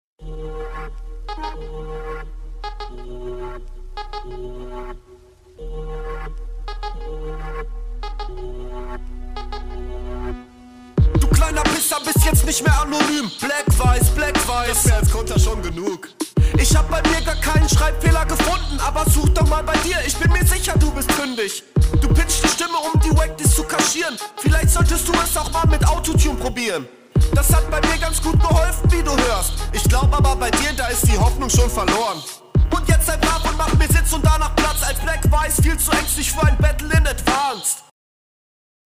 Flow: Finde ich flowlich schon cooler als bei der HR, wirkt auch abwechslungsreicher.
Flow ist cool, Mische etwas unangenehm in highs, da ist so ein fiepen wie bei …